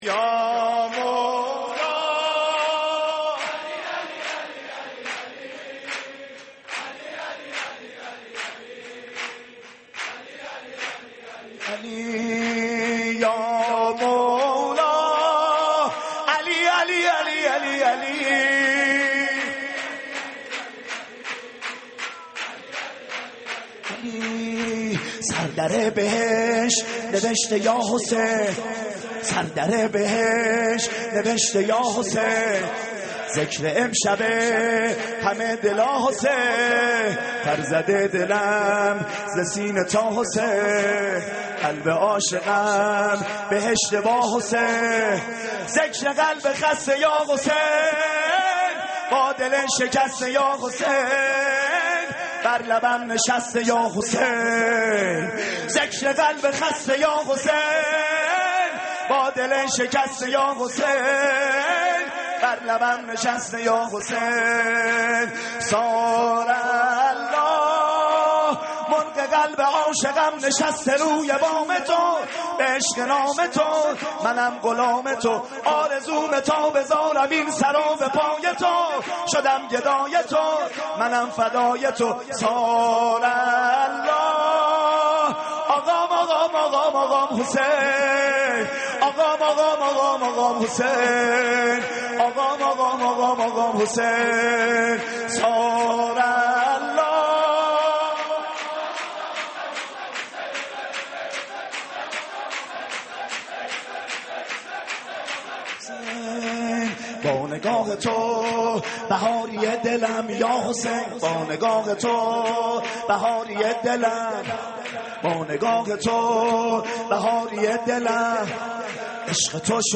مولودی ویژه ولادت امام حسین علیه‌السلام